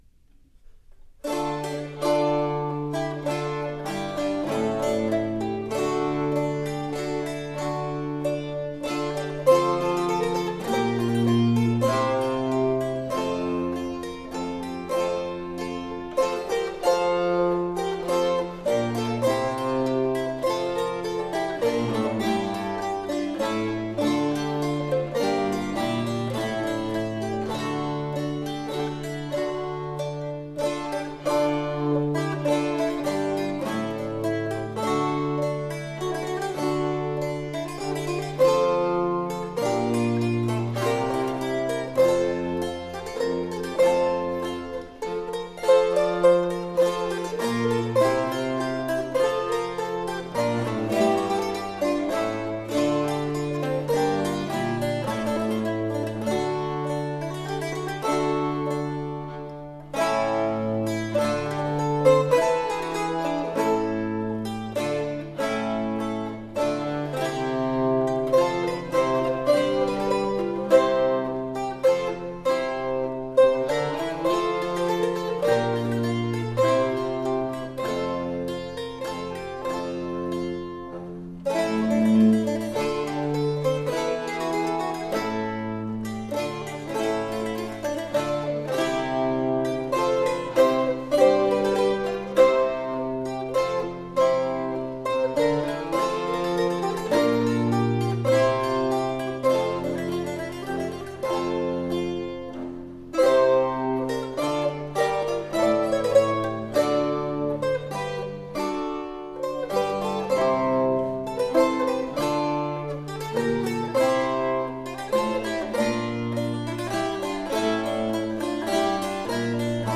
cittern with a bass
features cittern with bass viol